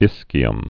(ĭskē-əm)